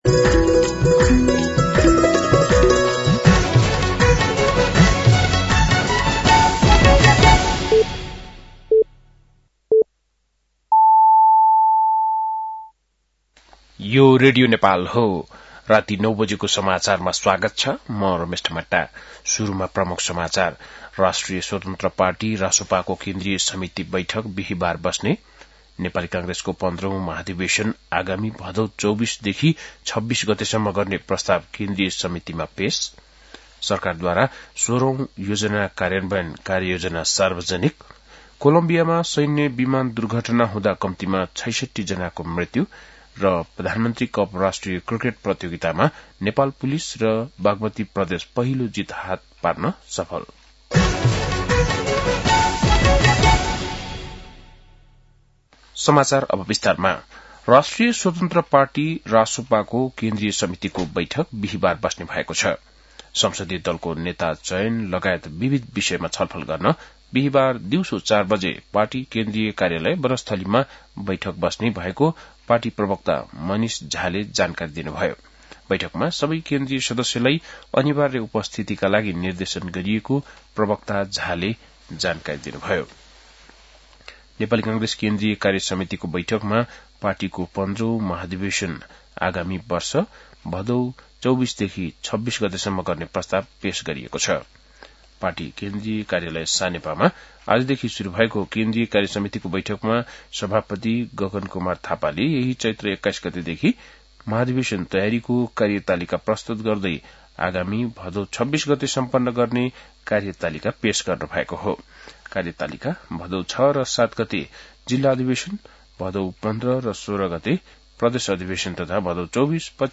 बेलुकी ९ बजेको नेपाली समाचार : १० चैत , २०८२